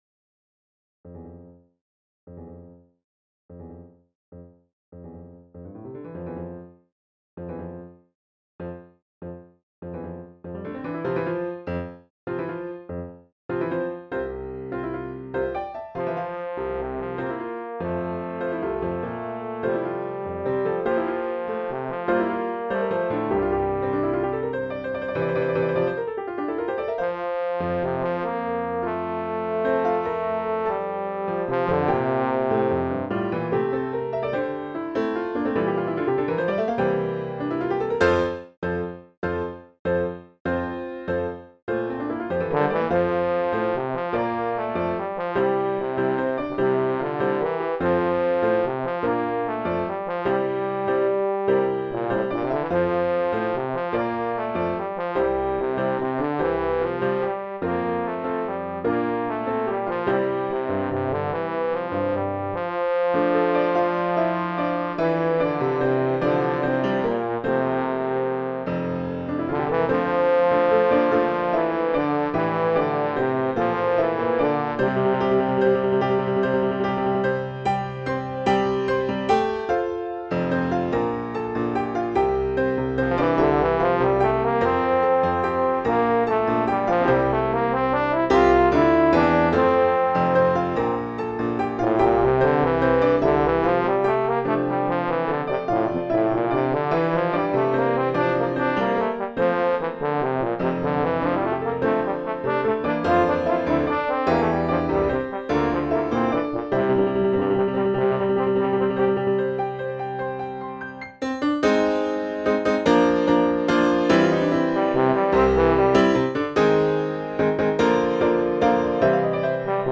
Transcription for Bass Trombone and Piano